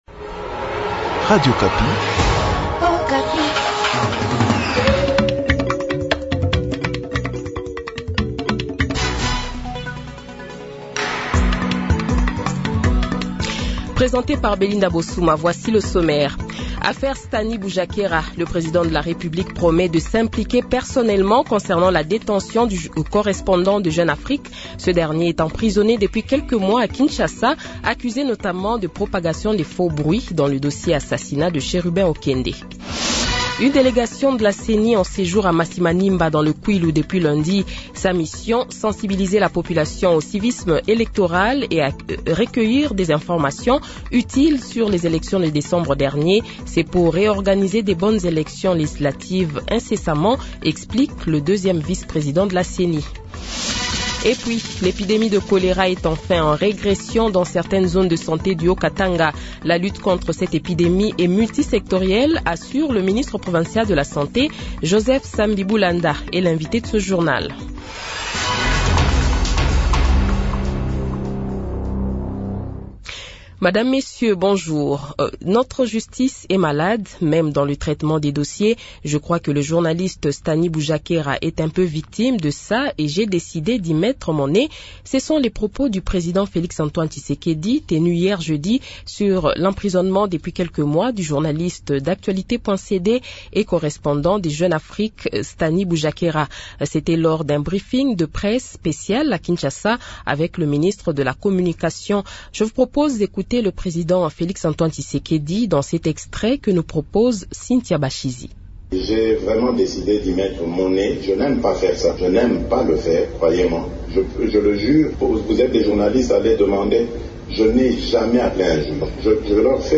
Journal Francais Midi
Le Journal de 12h, 23 Fevrier 2024 :